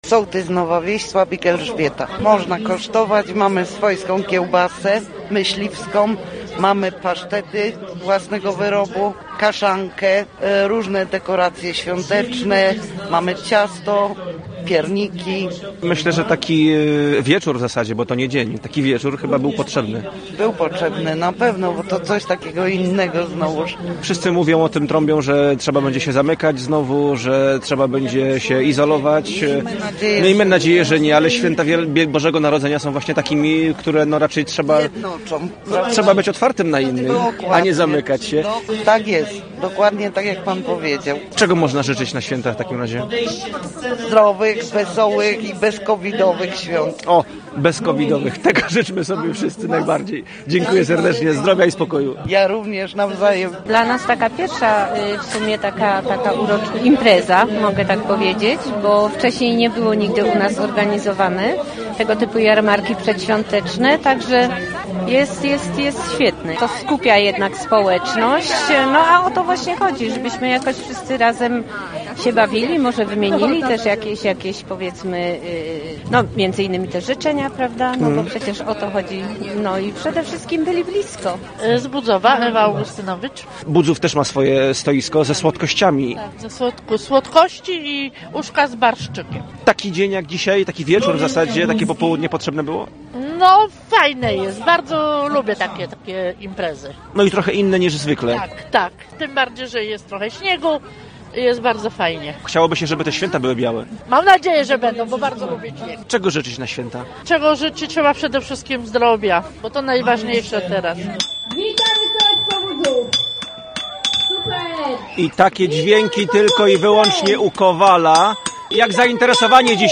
To dla nas pierwsza tego typu impreza, bo wcześniej takie jarmarki nie były organizowane – mówi jedna z mieszkanek Gorzowa, która skorzystała z zaproszenia organizatorów i sobotni wieczór (jarmark odbył się w sobotę 11 grudnia) spędziła przy miejskim amfiteatrze.